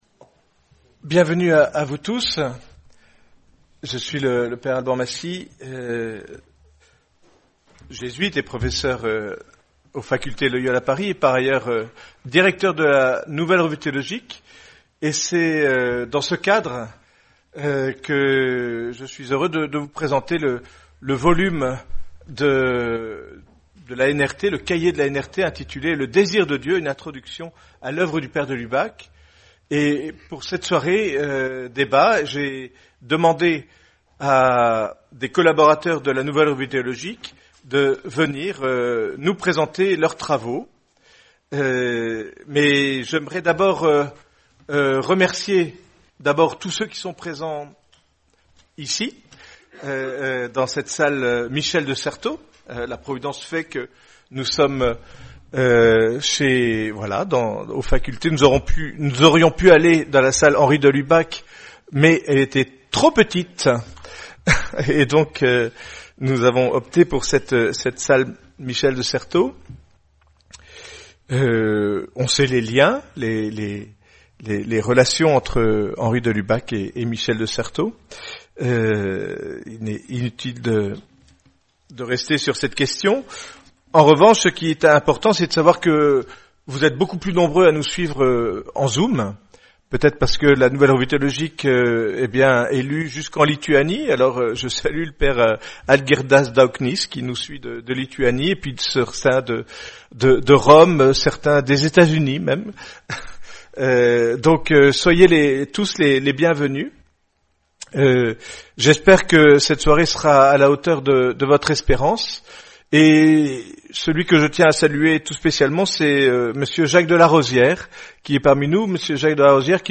Débat animé par